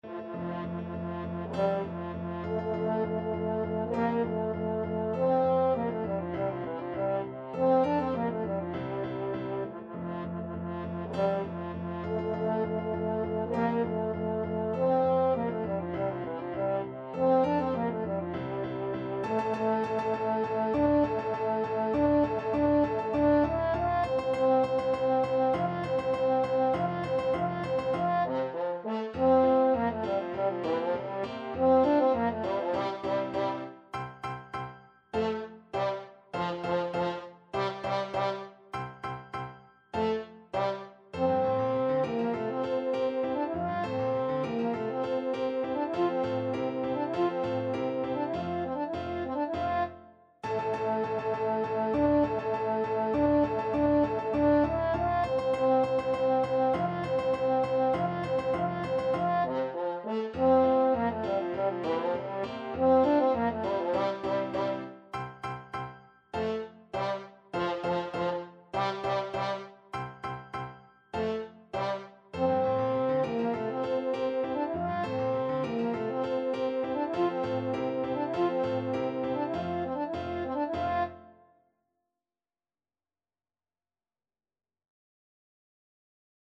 French Horn
F major (Sounding Pitch) C major (French Horn in F) (View more F major Music for French Horn )
March = c.100
2/2 (View more 2/2 Music)
C4-F5
Classical (View more Classical French Horn Music)
yorckscher_marsch_HN.mp3